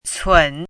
“忖”读音
cǔn
忖字注音：ㄘㄨㄣˇ
国际音标：tsʰuən˨˩˦